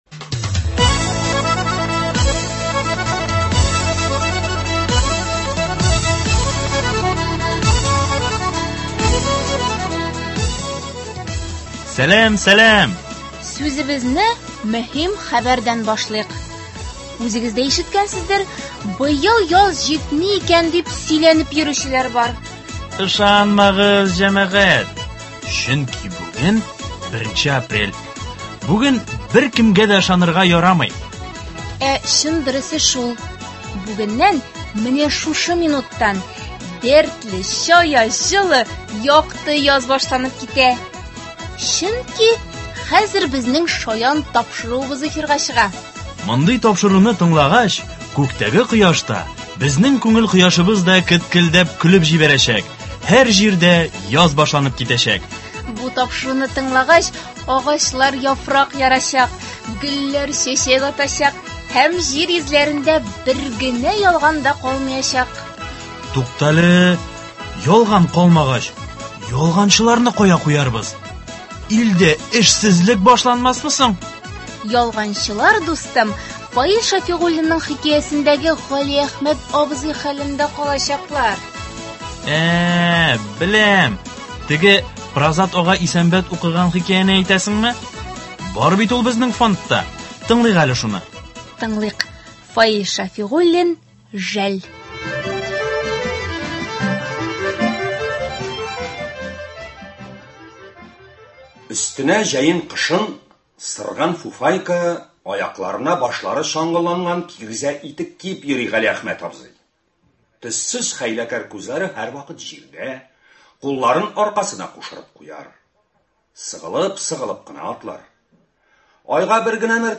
Юмористик программа.
Сез анда шаян җырлар, татар язучыларының нәфис сүз осталары башкаруында юмористик һәм сатирик хикәяләрен ишетә аласыз.